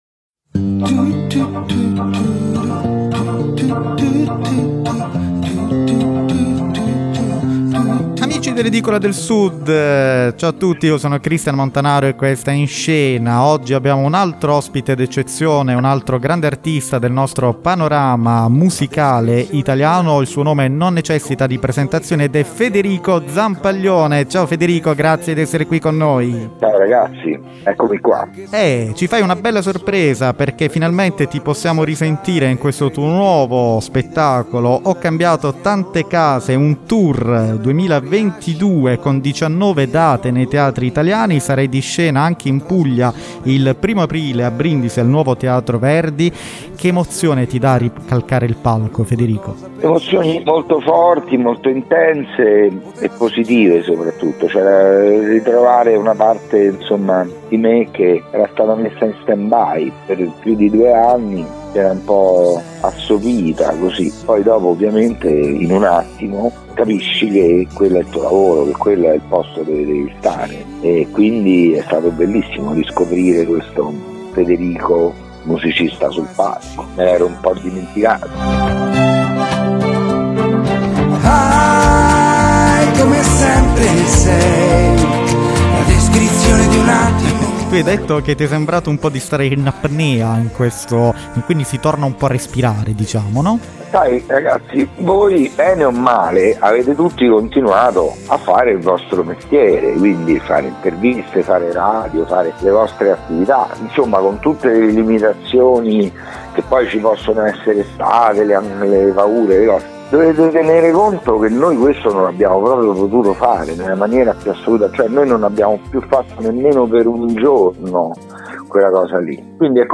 Ep.11 - Federico Zampaglione: «Ho cambiato tante case» - L'INTERVISTA
Intervista a Federico Zampaglione dei Tiromancino.